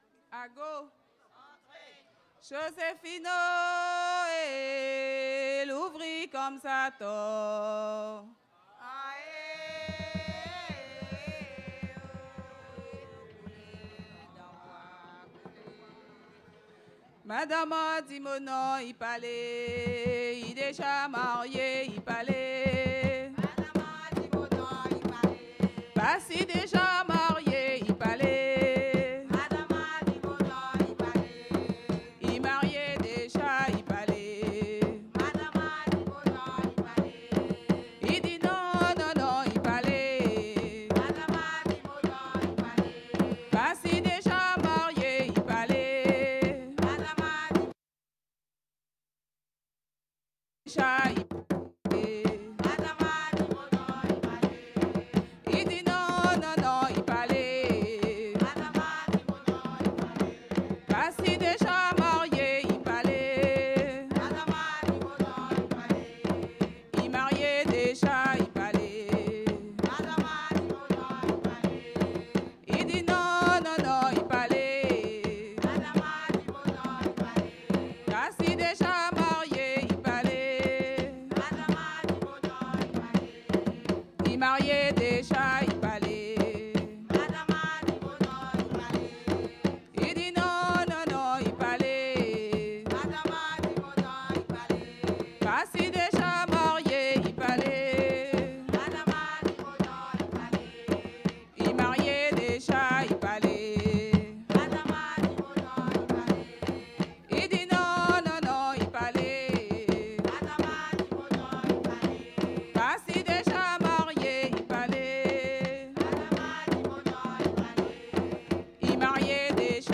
Saint-Georges-de-l'Oyapoc
danse : ka(n)mougé (créole)
Pièce musicale inédite